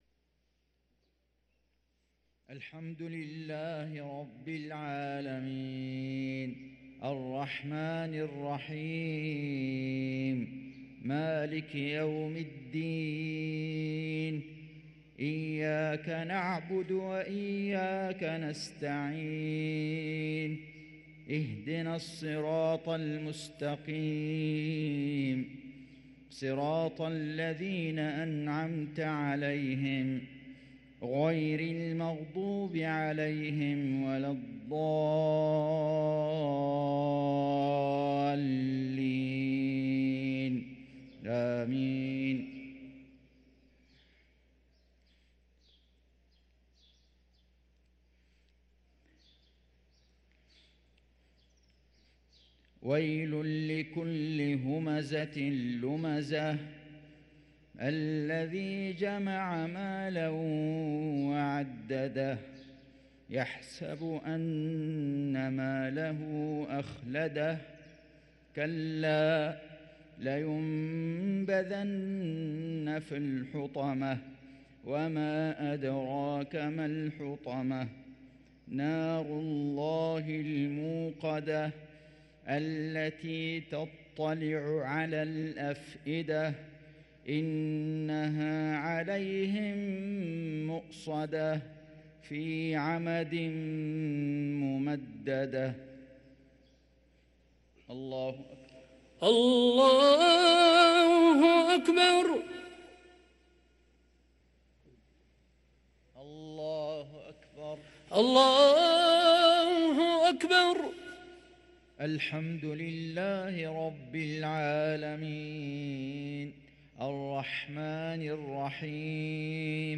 صلاة المغرب للقارئ فيصل غزاوي 2 رجب 1444 هـ
تِلَاوَات الْحَرَمَيْن .